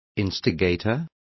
Complete with pronunciation of the translation of instigator.